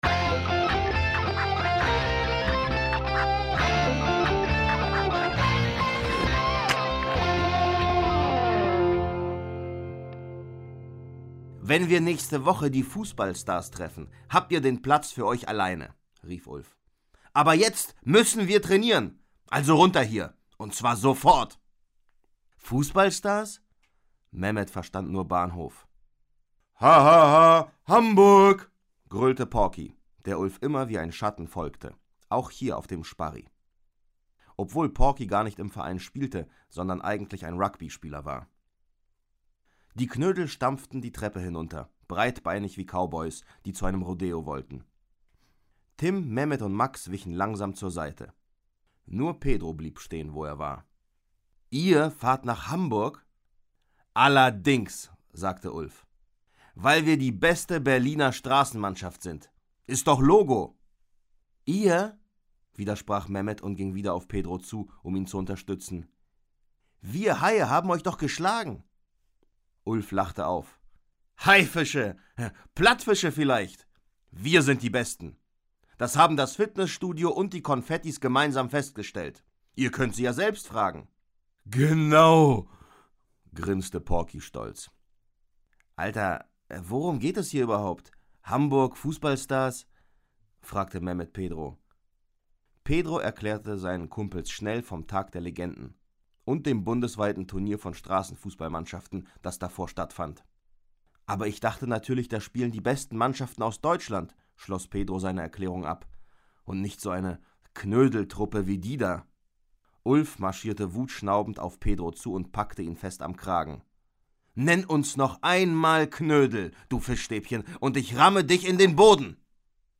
Schlagworte Fußball; Kinder-/Jugendliteratur • Hörbuch; Lesung für Kinder/Jugendliche • Kickers • Kinder/Jugendliche: Sportromane • Straßenfußball • Trainer